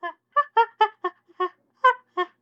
maj : you can find 2 samples of chimpanzee... very impatient to hear your creations.
Chimpanzee2.wav